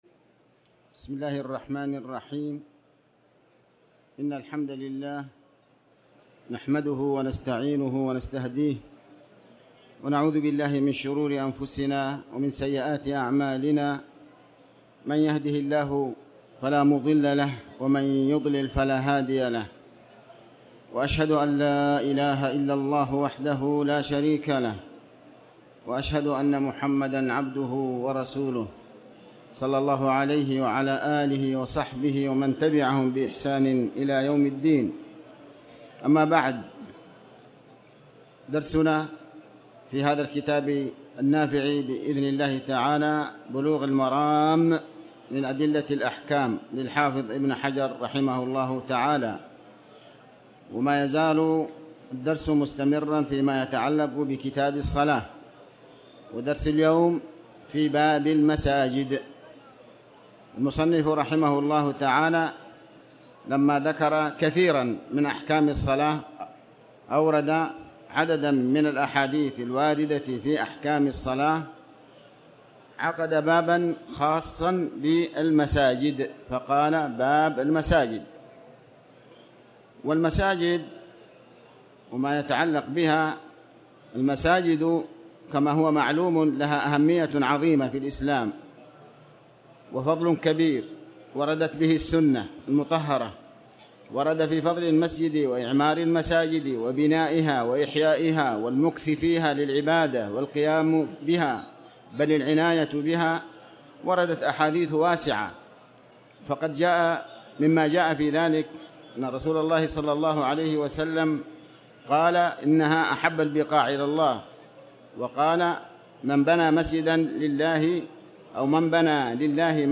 تاريخ النشر ٢٦ رجب ١٤٣٩ هـ المكان: المسجد الحرام الشيخ